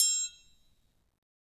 Triangle3-HitM_v1_rr1_Sum.wav